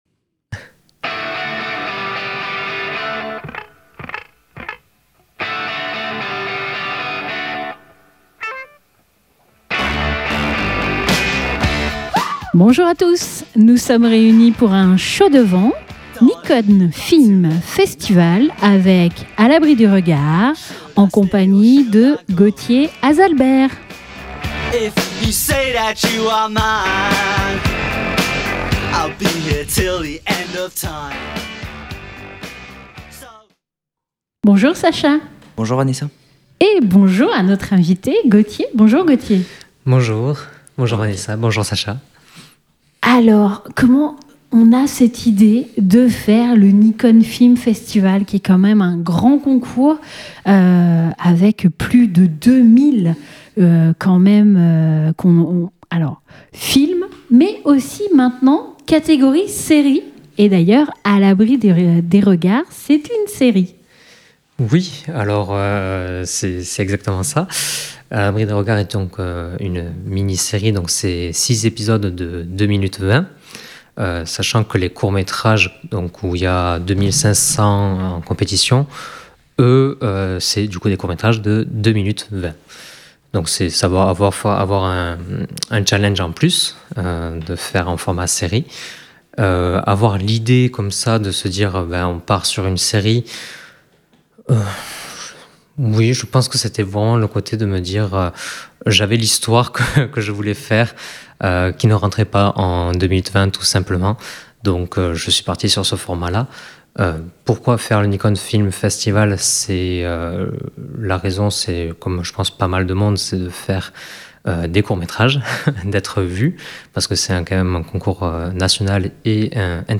invité dans notre studio